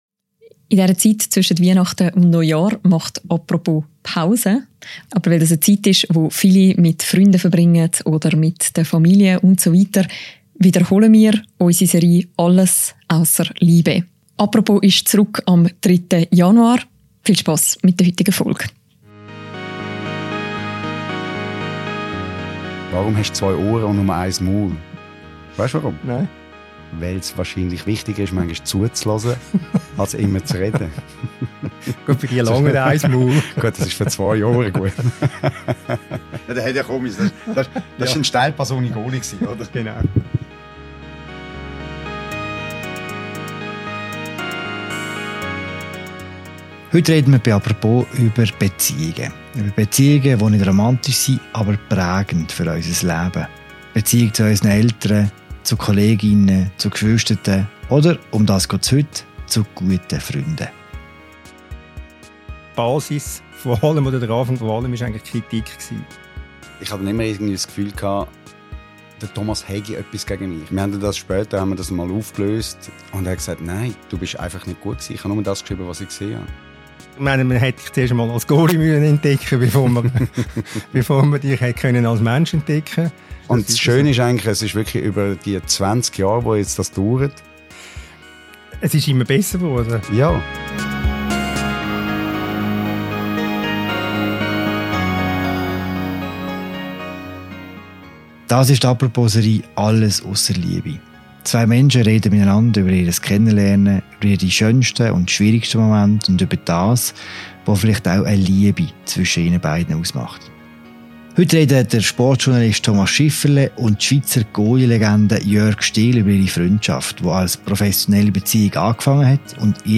In der dritten Folge der «Apropos»-Spezialserie «Alles ausser Liebe» sprechen die beiden Männer über ihre anfänglichen Feindseeligkeiten, darüber in Männerfreundschaften über Gefühle zu sprechen und lachen über Fussball und Journalismus.